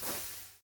Minecraft Version Minecraft Version snapshot Latest Release | Latest Snapshot snapshot / assets / minecraft / sounds / block / candle / extinguish3.ogg Compare With Compare With Latest Release | Latest Snapshot
extinguish3.ogg